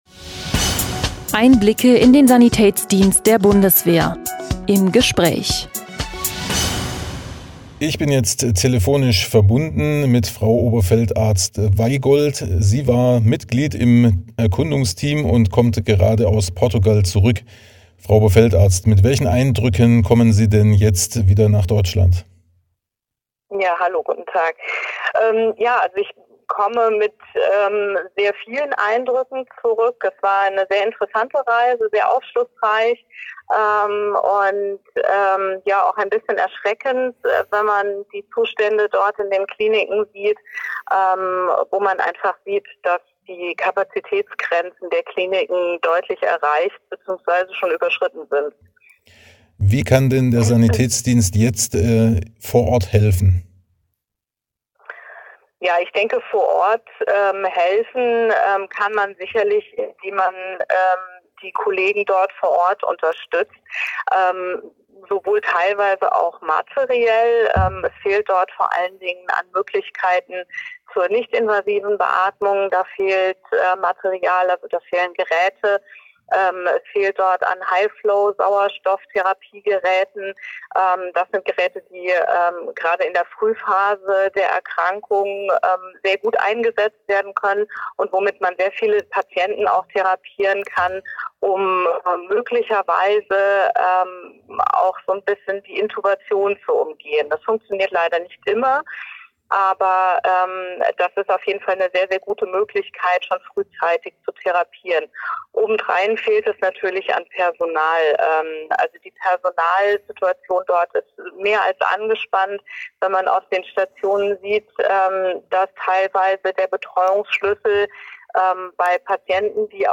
Nach ihrer Rückkehr aus Portugal haben wir ein Telefoninterview mit ihr geführt.